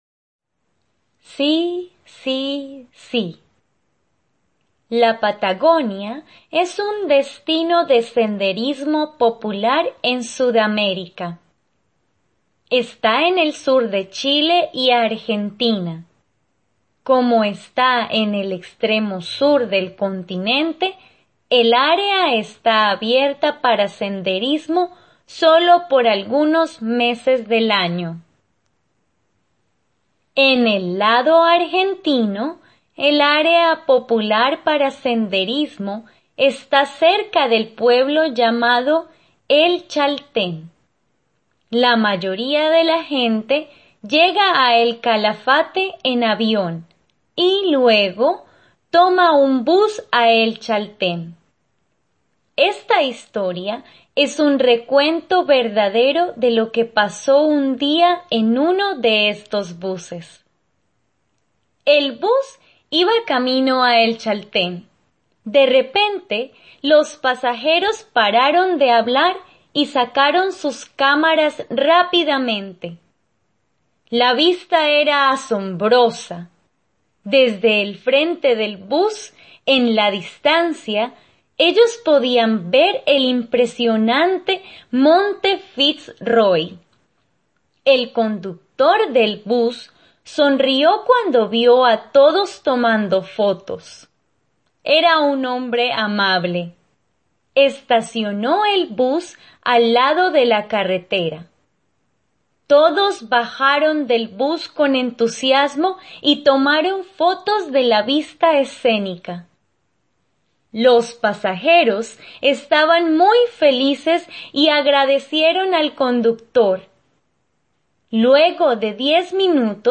Elementary Spanish Short Story